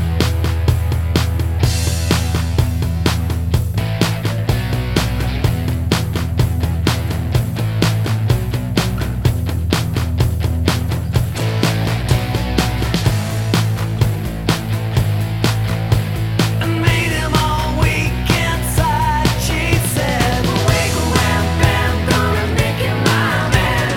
no Backing Vocals Glam Rock 3:09 Buy £1.50